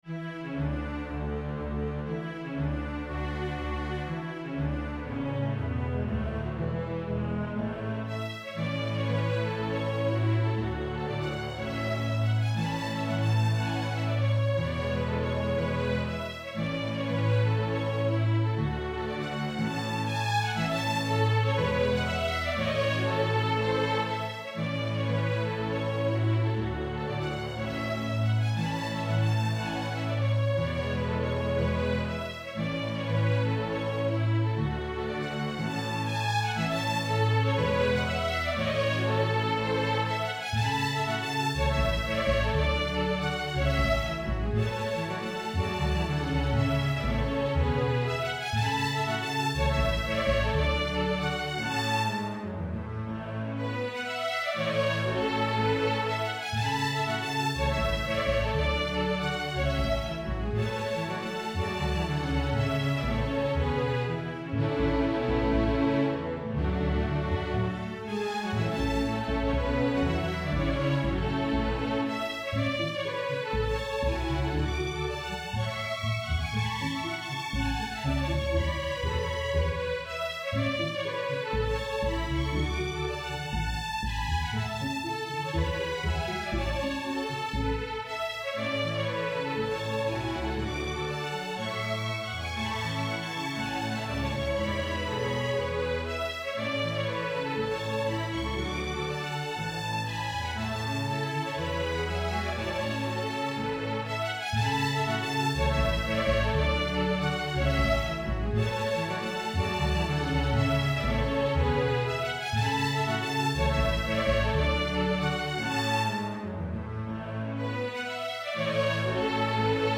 ADVANCED, STRING QUARTET
Notes: mordent, dotted rhythms
1st Violin 3rd position
Key: A major